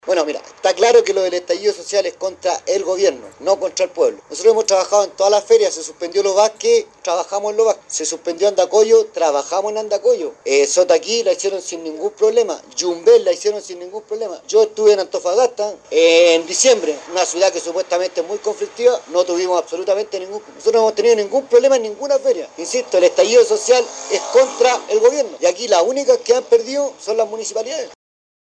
Al respecto, el equipo de prensa de Nostálgica se trasladó al lugar donde se están instalando algunos feriantes para conocer de primera mano la opinión al respecto.